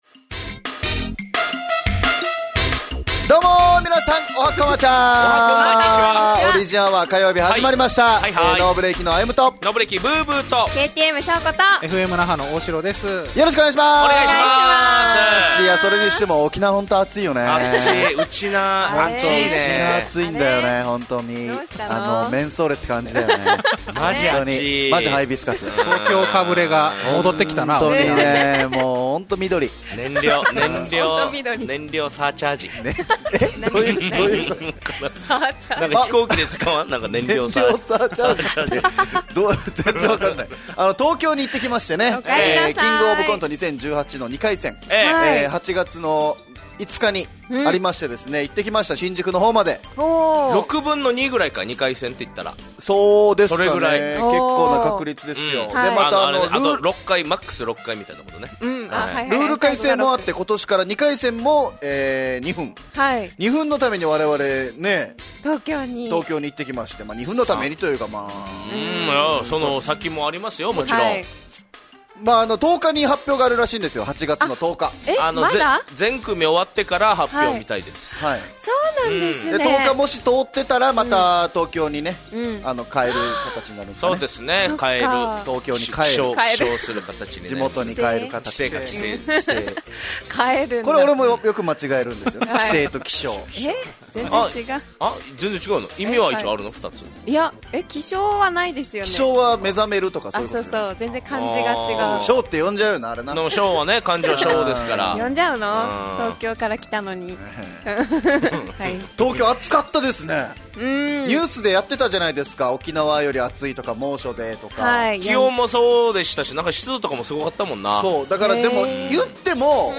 fm那覇がお届けする沖縄のお笑い集団・オリジンメンバー出演のバラエティ番組